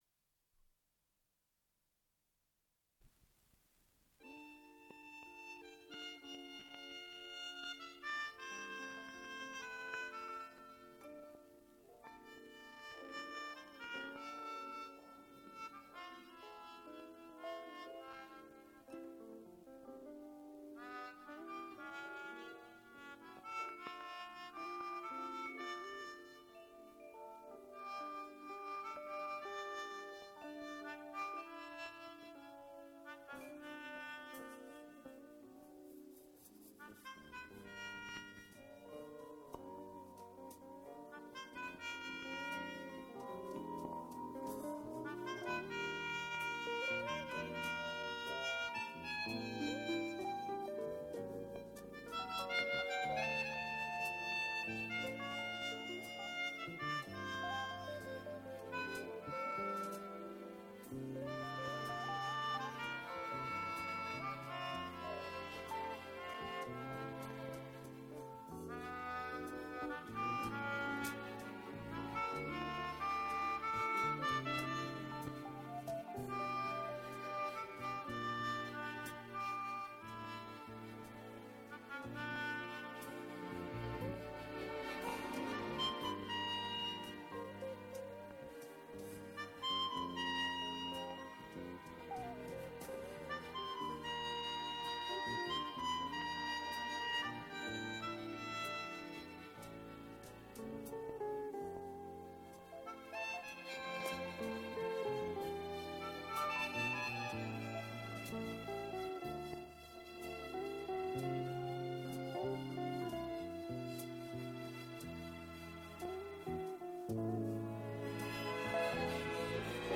Запись 1982г. Дубльмоно.